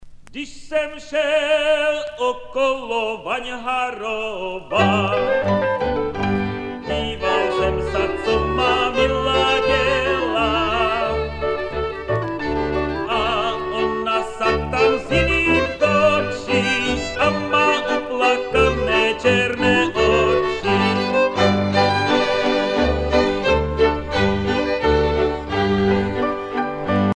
Folk singer from Moravian Wallachia
Pièce musicale éditée